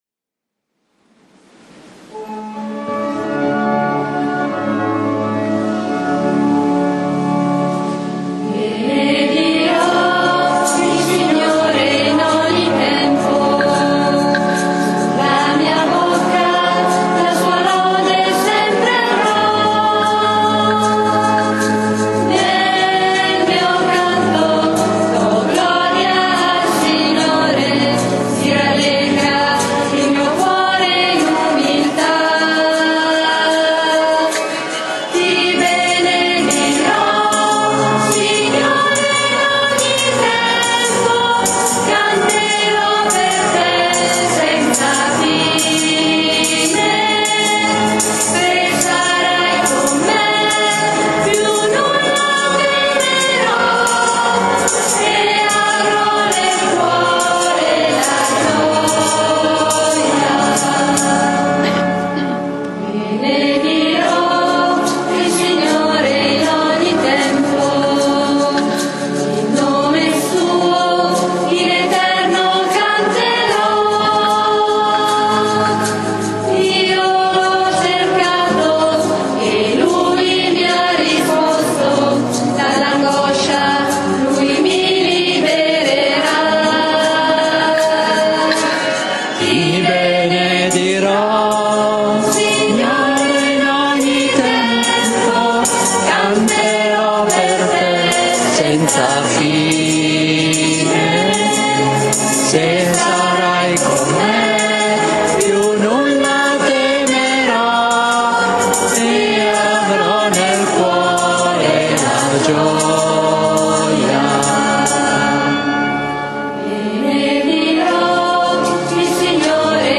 SOLENNITA' DI CRISTO RE
canto: Benedirò il Signore